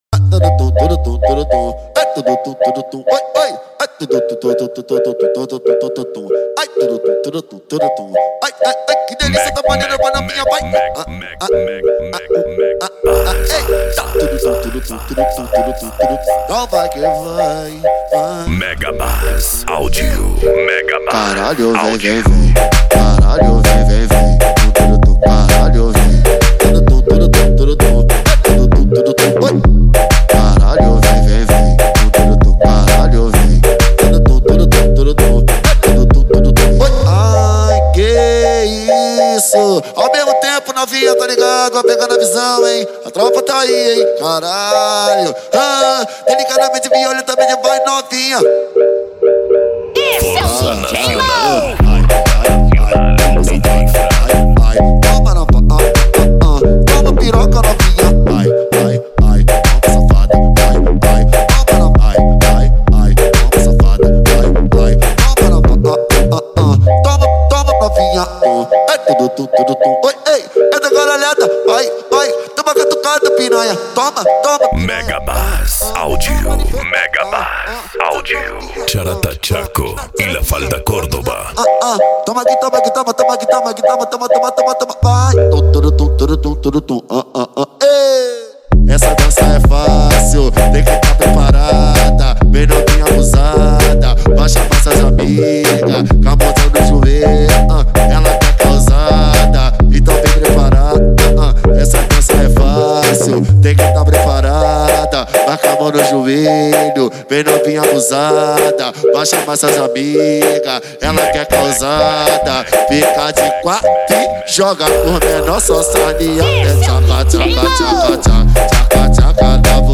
Bass
Funk
Mega Funk
Minimal